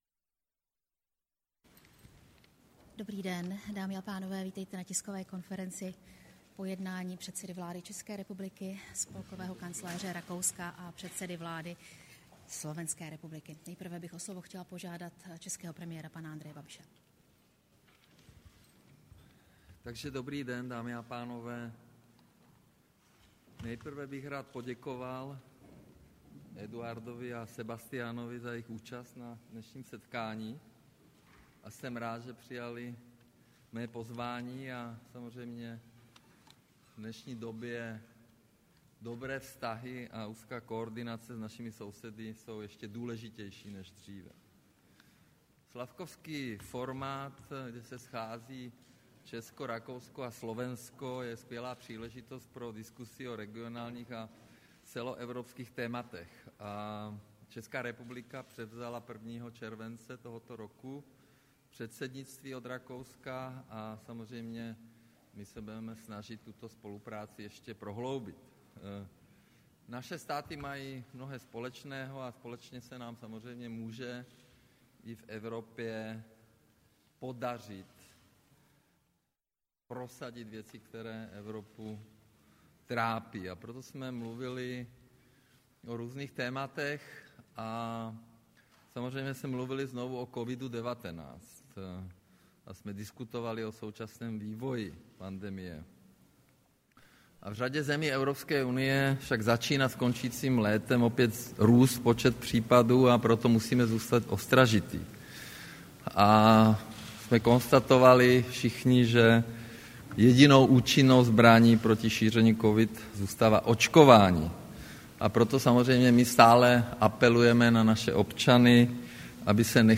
Tisková konference po jednání Slavkovského formátu, 7. září 2021